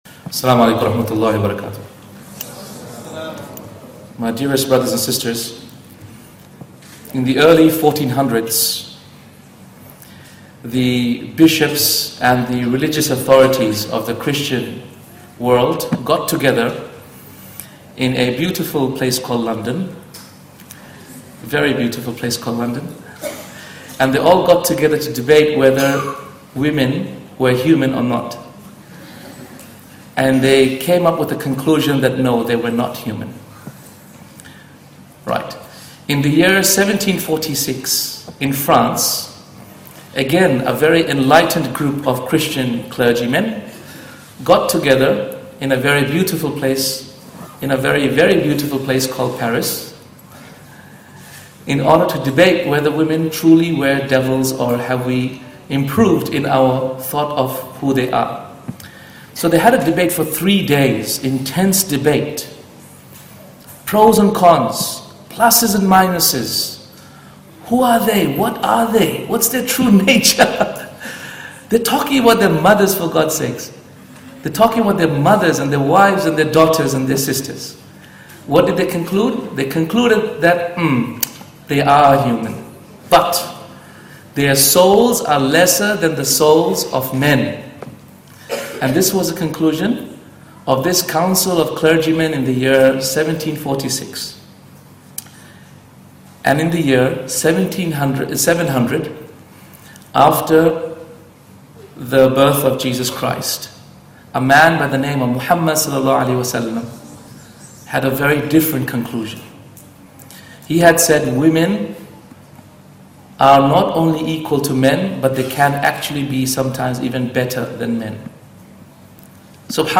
In this powerful lecture featured on The Deen Show, we explore how Islam elevated the status of women over 1,400 years ago, long before any modern women’s liberation movement. The speaker takes us through a stunning historical comparison: in 1400s London, Christian bishops debated whether women were even human.